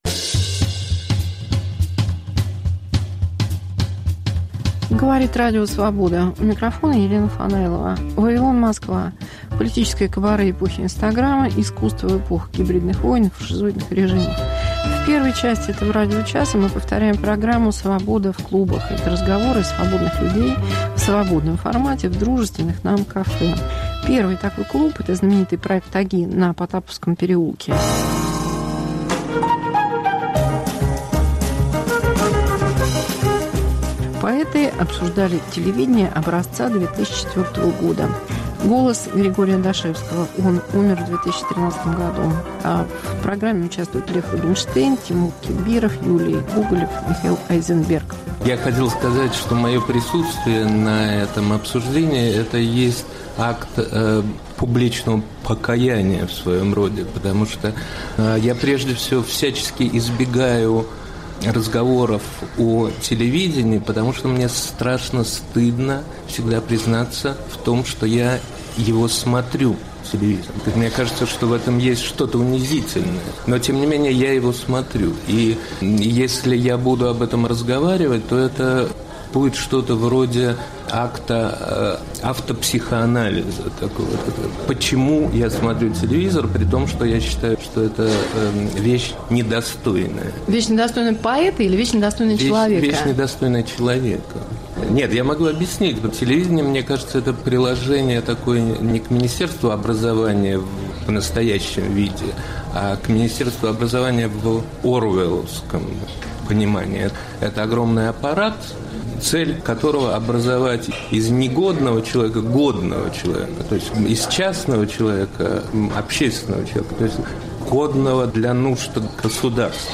Мегаполис Москва как Радио Вавилон: современный звук, неожиданные сюжеты, разные голоса. ТВ как домашнее животное. Год ковида: власть и вирус.